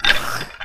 PixelPerfectionCE/assets/minecraft/sounds/mob/stray/idle1.ogg at mc116